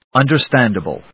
音節un・der・stand・a・ble 発音記号・読み方
/`ʌndɚstˈændəbl(米国英語), ˌʌndɜ:ˈstændʌbʌl(英国英語)/